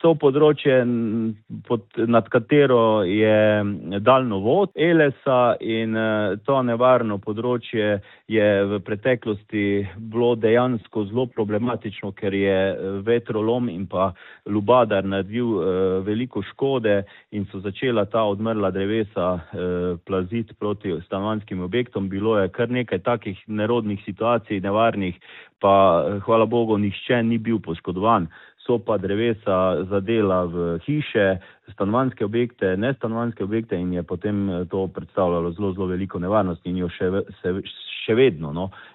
Bolj natančno razloži župan Miran Pušnik: